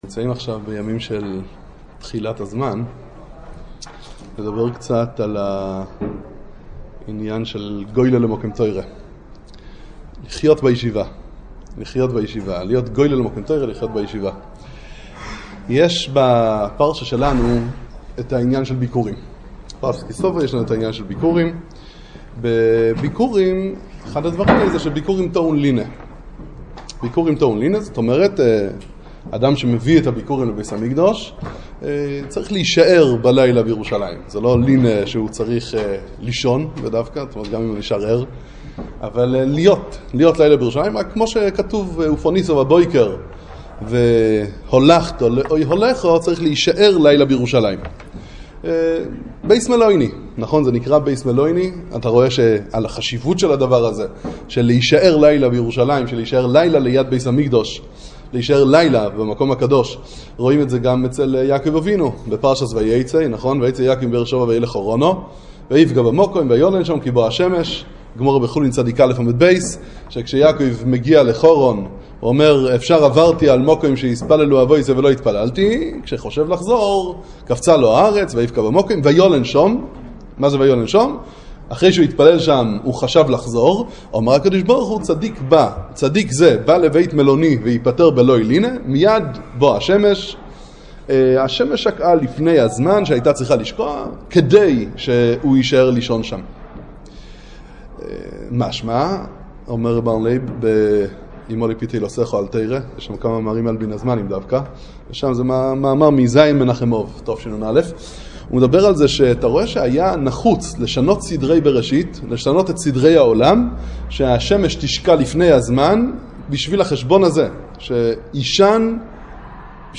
שיחת חיזוק לתחילת ישיבה גדולה - הוי גולה למקום תורה - מעלת הלינה והשהות בירושלים ובישיבה הקדושה
וועד לבחורי ישיבת ארחות תורה בני ברק